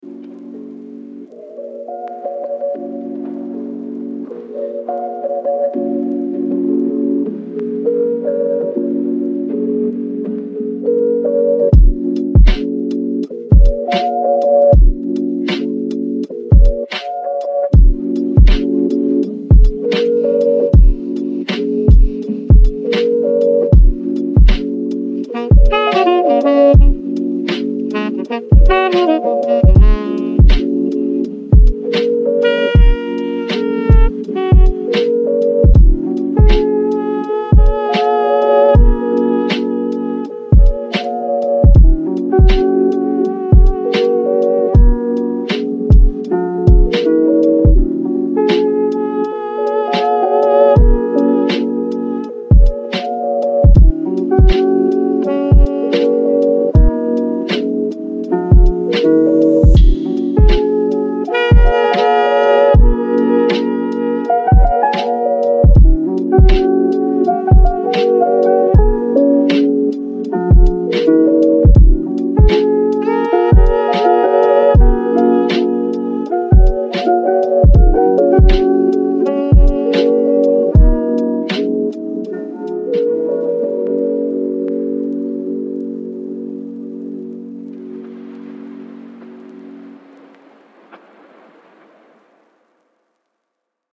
Lofi Hip Hop